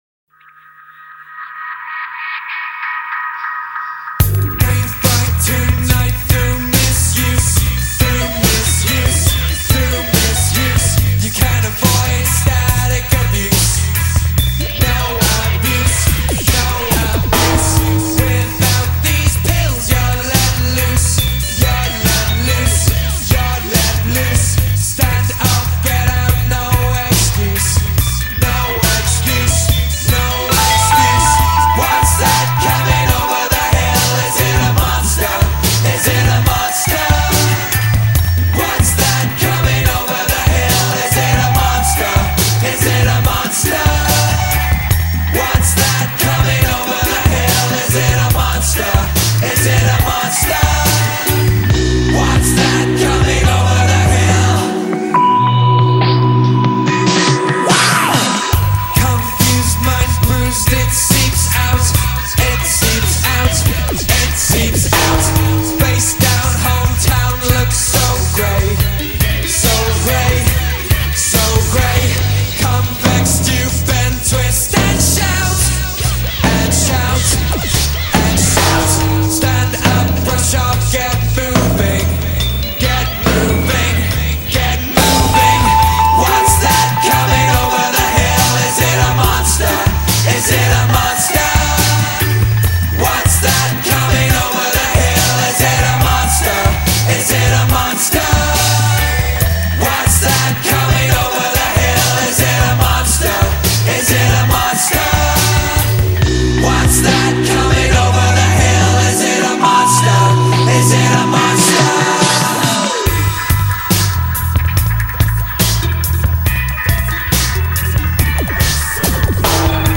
Bootlegs (page 8):